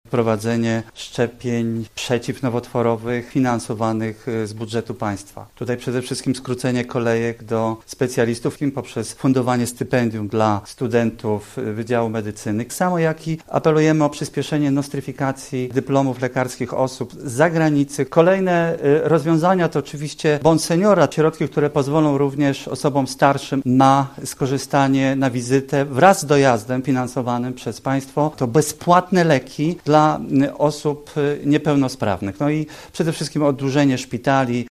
– Z badań wynika, że niedługo co czwarty Polak może być dotknięty chorobą nowotworową – mówił podczas konferencji prasowej Witold Pahl, kandydat Koalicji Obywatelskiej do Sejmu: